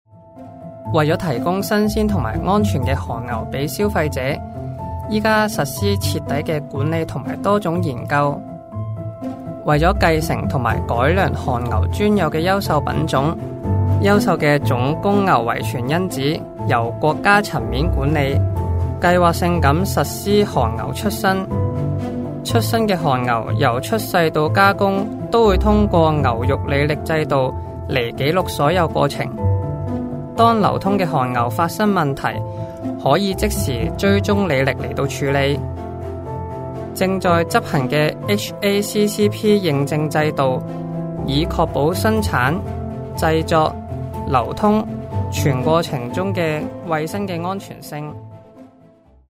Voice actor sample
차분/편안